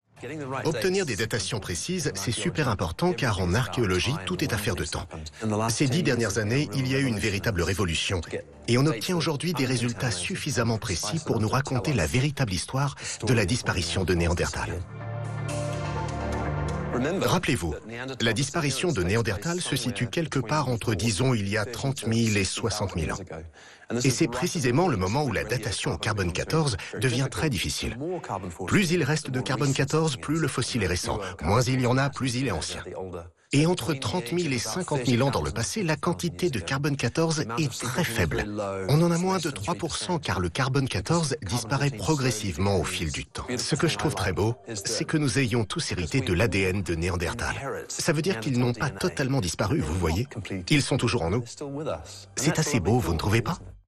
Hauteur de voix médium grave, jeu d'un scientifique à la diction précise